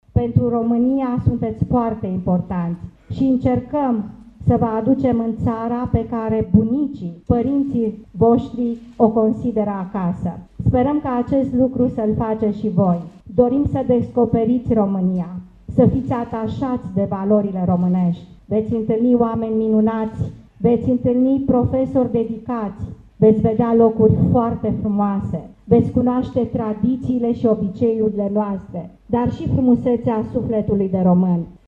Viorica Dăncilă a fost prezentă la deschiderea festivă a taberei de la Sulina, în care au venit copii din Republica Moldova şi Transcarpatia, Ucraina.
Premierul i-a îndemnat să descopere tradiţiile şi valorile româneşti şi le-a reamintit că sunt aşteptaţi să revină în ţară: